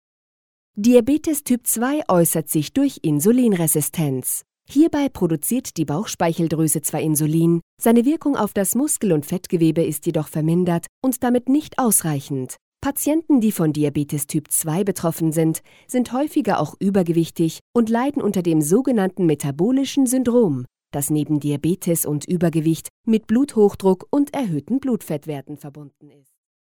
Warme, freundliche mittlere Stimmlage.
Sprechprobe: eLearning (Muttersprache):
Warm voice, middle aged
A-Plus Apothekenschulung E learning Diabtetes.mp3